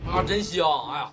原版真香音效_人物音效音效配乐_免费素材下载_提案神器